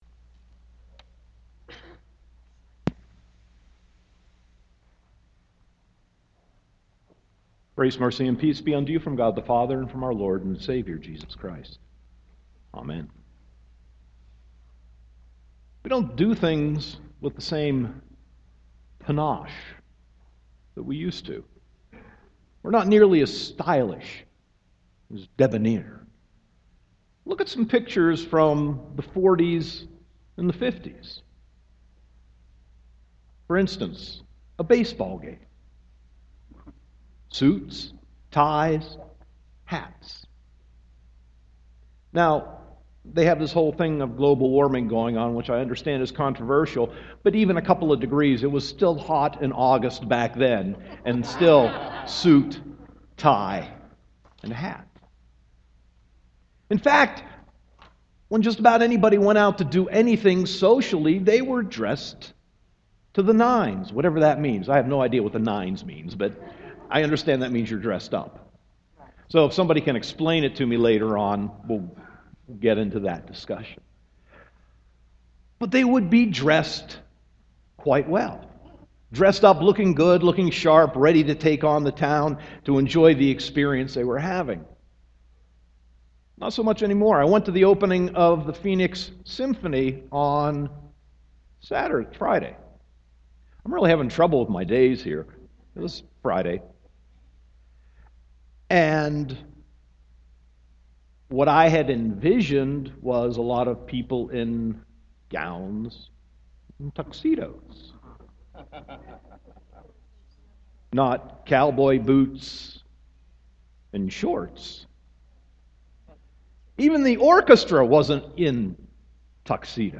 Sermon 9.20.2015